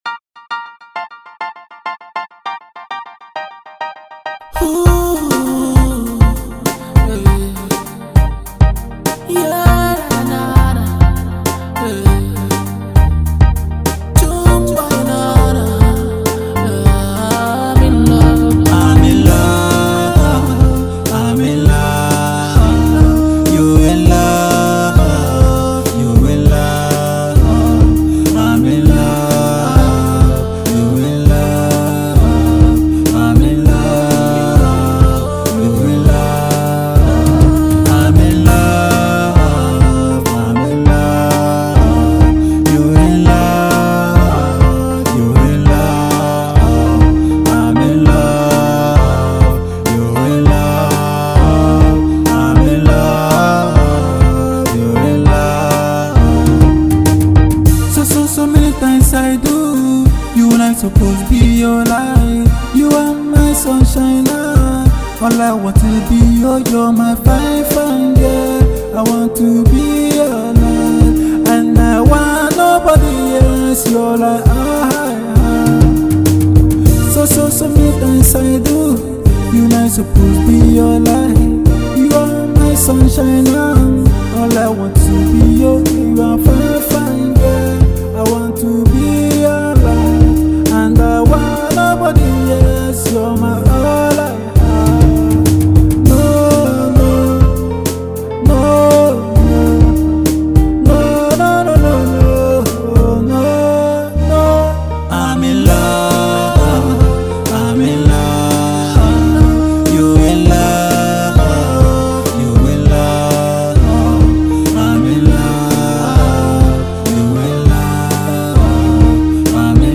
studio project